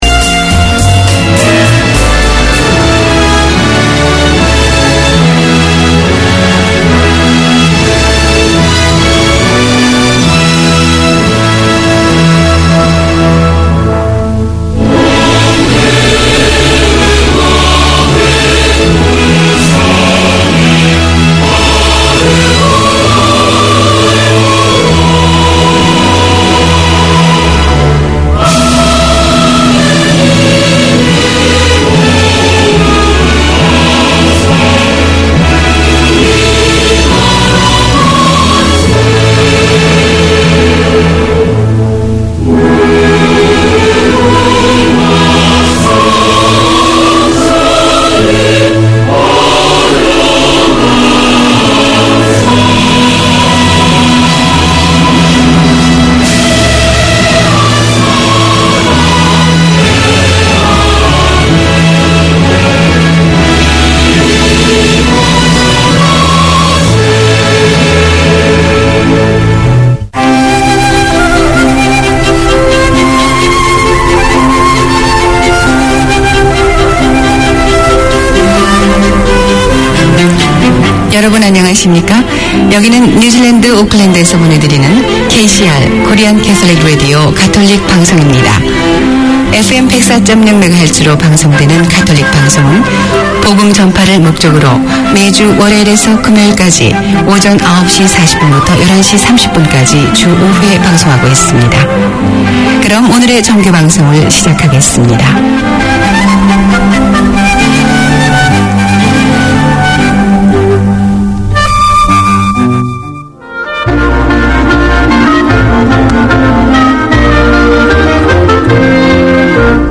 Community Access Radio in your language - available for download five minutes after broadcast.
From local legends in grassroots to national level names, the Sports Weekender features interviews, updates and 'the week that was'.